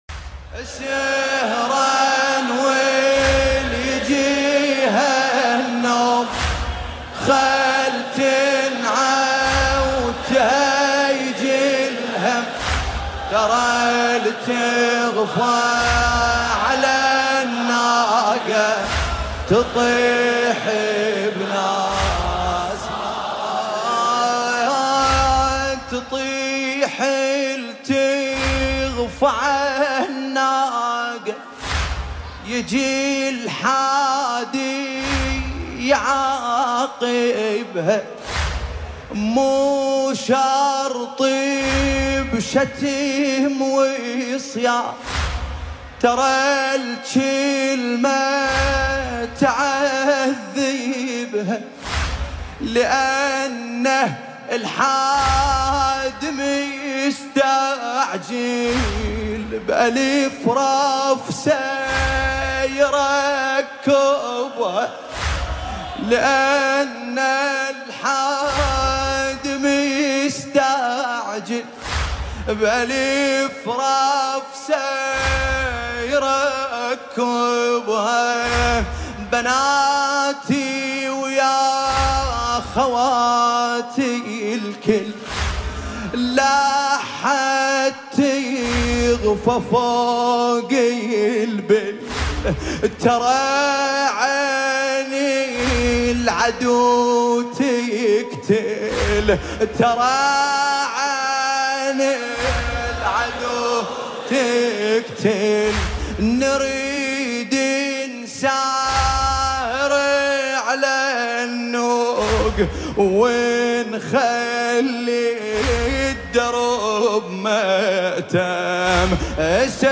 شهر محرم الحرام 1447 هـ